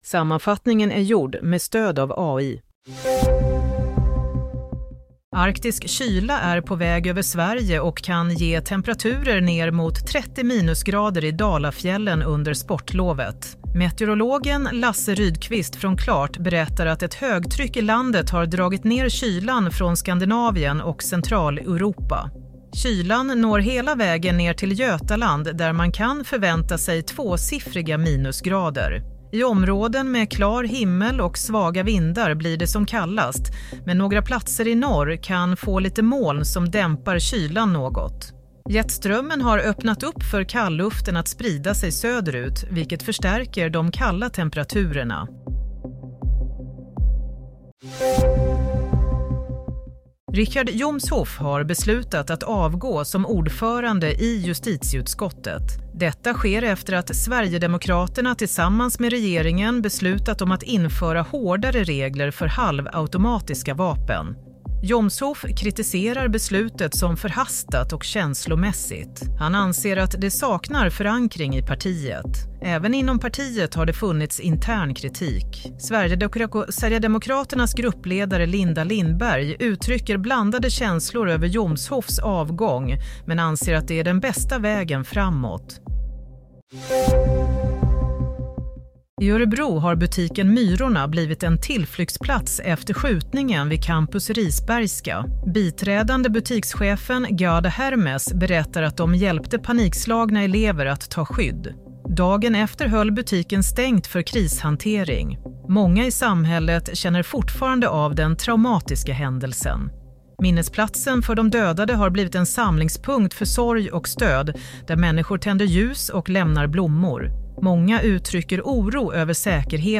Nyhetssammanfattning - 10 februari 22.40